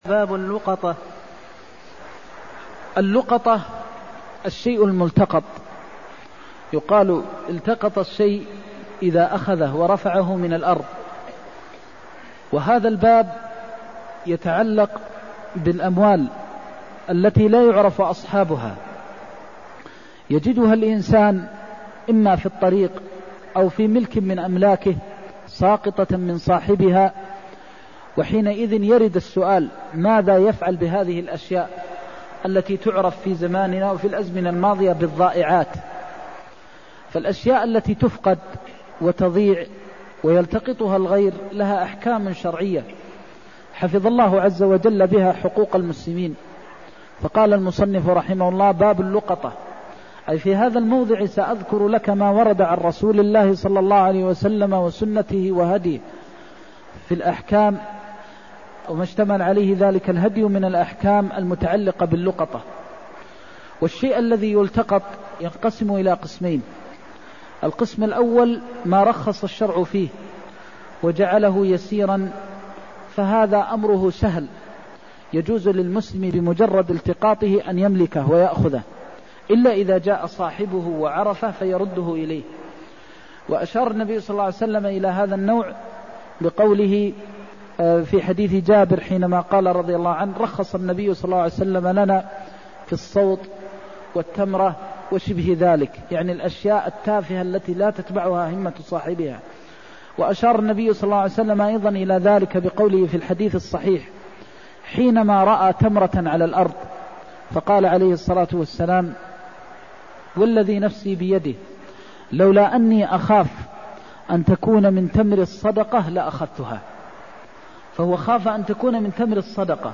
المكان: المسجد النبوي الشيخ: فضيلة الشيخ د. محمد بن محمد المختار فضيلة الشيخ د. محمد بن محمد المختار هديه في اللقطة وضالة الإبل والغنم (277)